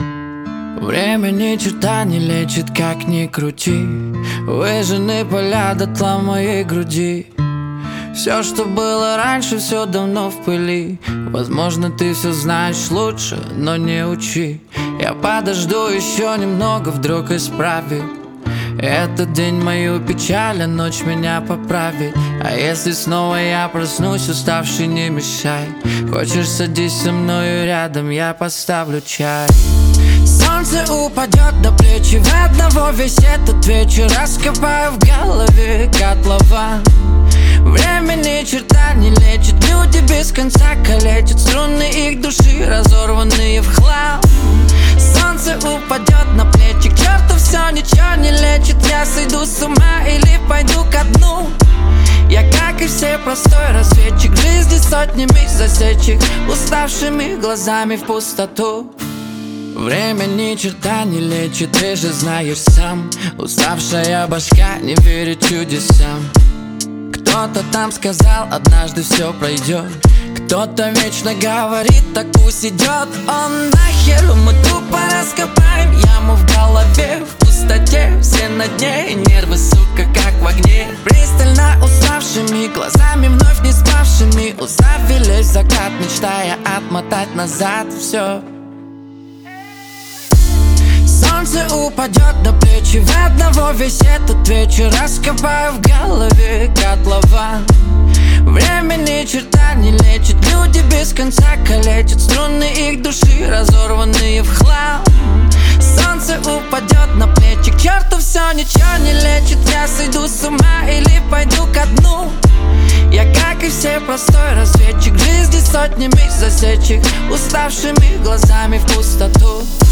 это эмоциональный трек в жанре поп-рэп